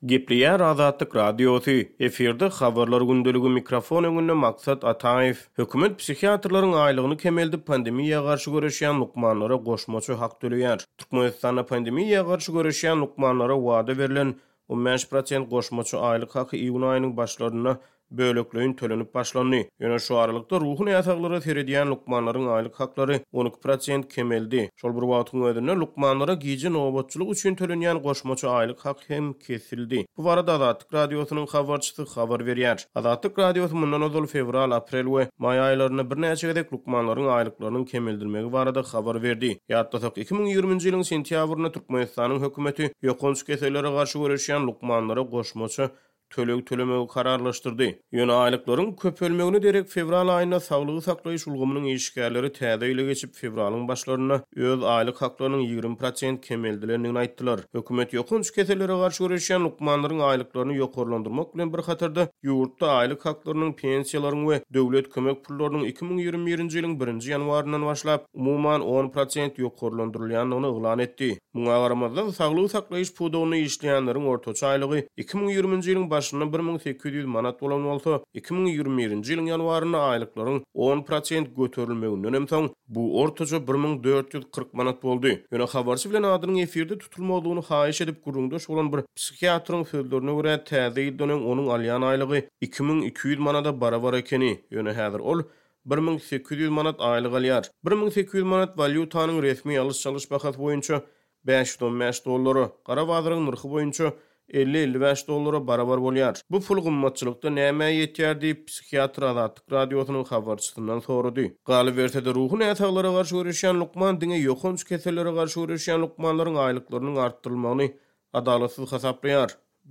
Bu barada Azatlyk Radiosynyň habarçysy habar berýär.